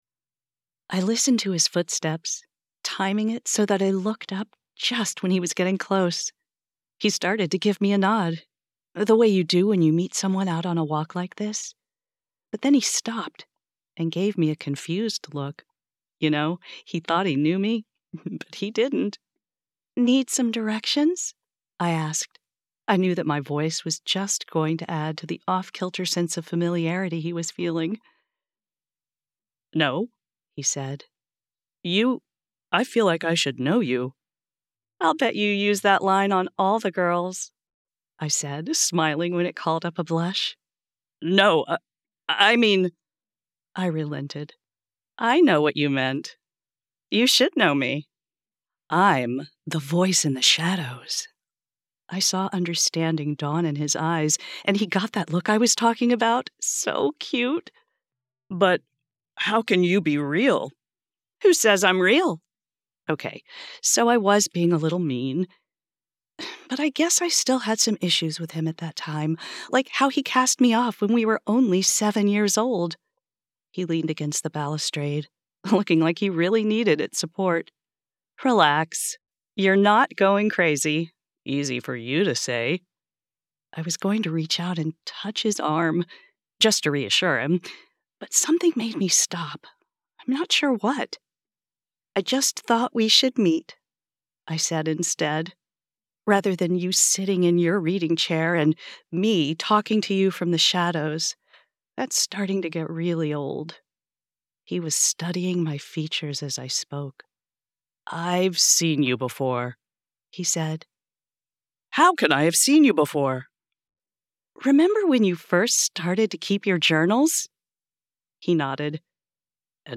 Romantasy | 1st MF dialogue | Shadow spirit confesses love
Professional Home Studio
- Professional Sound Treatment